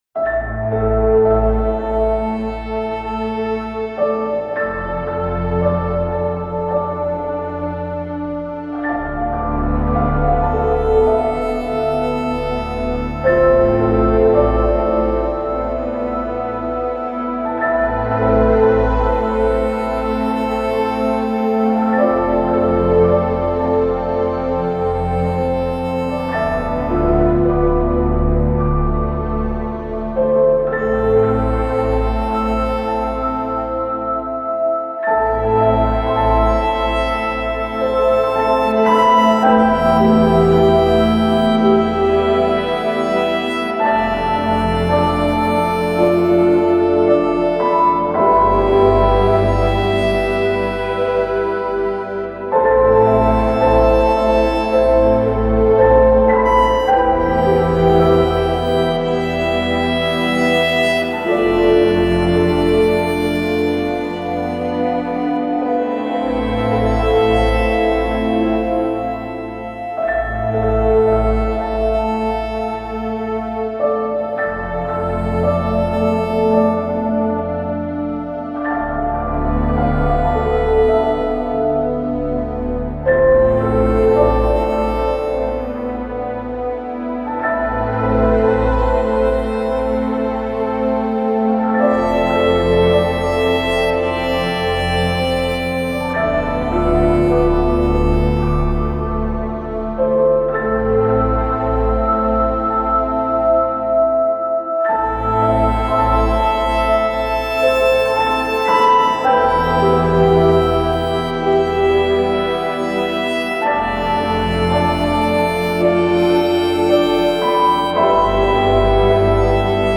پیانو
عصر جدید , عمیق و تامل برانگیز , غم‌انگیز